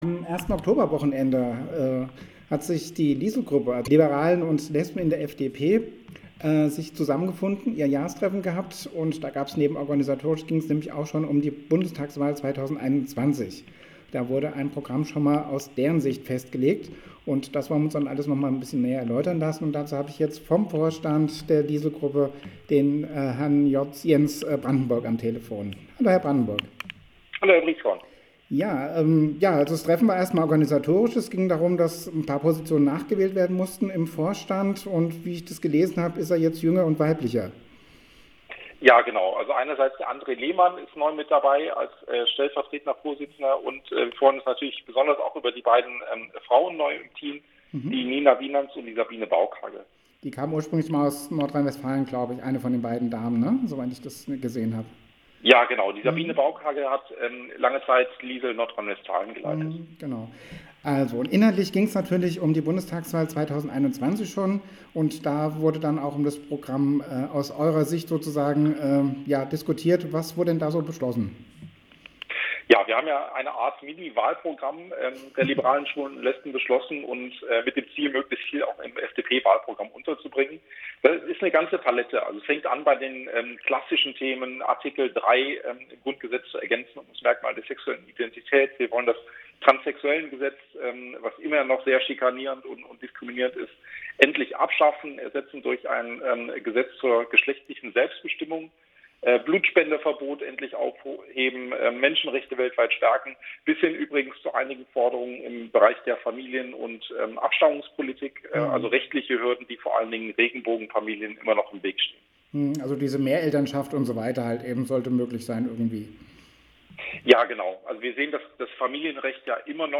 Der queerpolitische Sprecher der FDP, Jens Brandenburg, im Gespräch